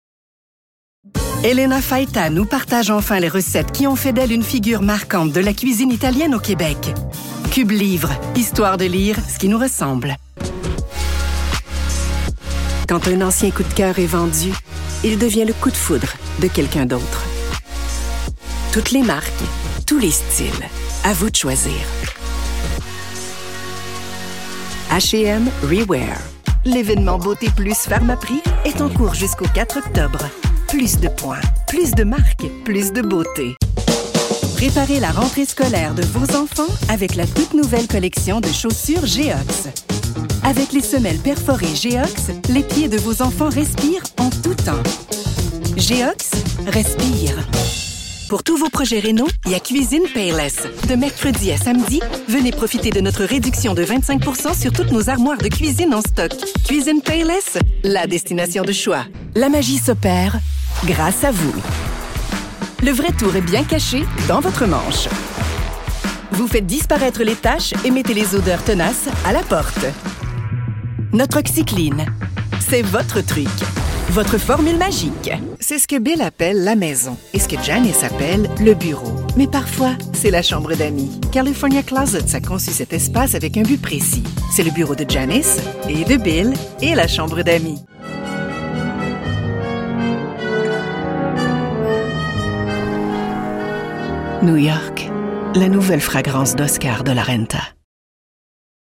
Locutora francocanadiense verdaderamente profesional, nativa de Quebec, l...
Versátil
Cálido